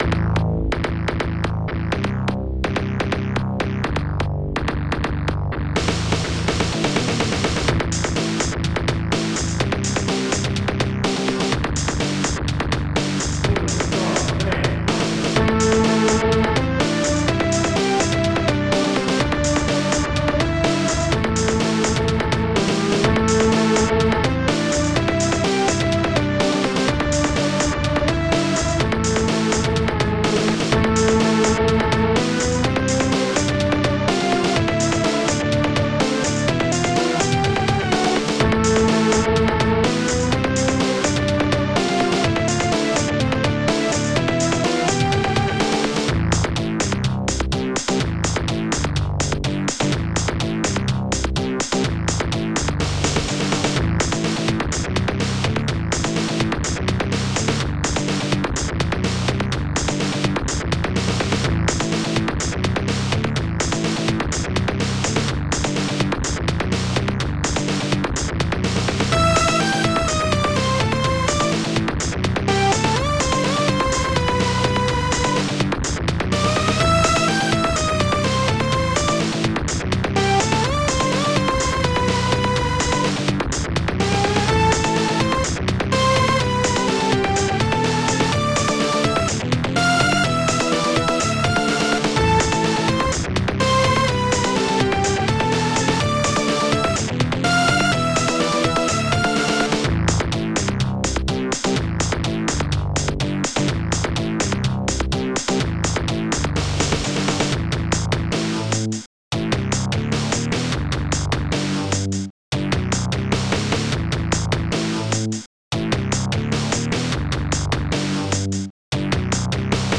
st-09:bass -acid st-09:bdrum-4 st-09:snare-5 st-09:hihat-2 ST-08:klick st-09:effect-123 ST-08:wring st-09:lead -1